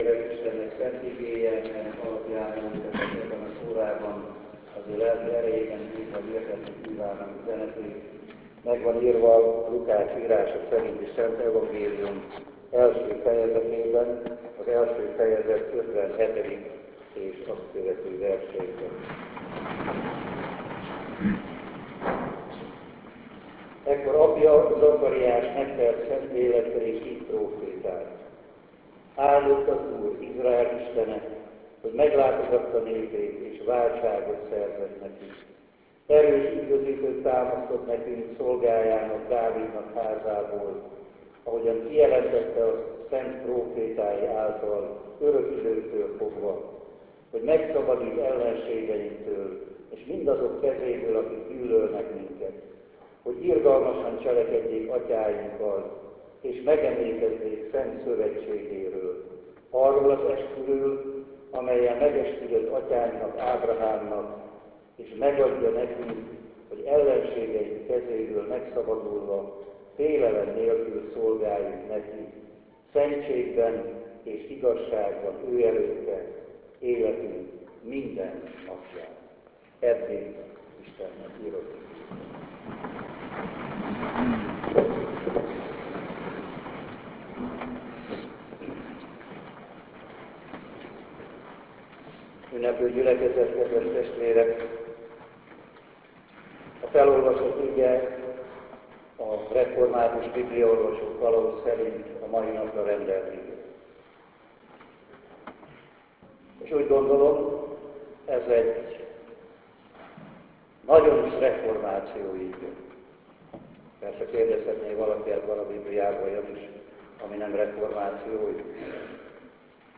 Budapest – Ökumenikus istentiszteletre gyűltek össze a reformáció egyházainak képviselői a budavári evangélikus templomban 2012. október 28-án.
Igehirdetéssel – mint azt korábbi cikkünkben is írtuk – dr. Bölcskei Gusztáv református püspök szolgált.
Az igehirdetés hangfelvétele a mellékelt linkről letölthető. Fájlok Bölcskei Gusztáv igehirdetése 1.4 MB Linkek BÖLCSKEI GUSZTÁV: FÉLELEM NÉLKÜL SZOLGÁLJUNK ISTENNEK Dokumentummal kapcsolatos tevékenységek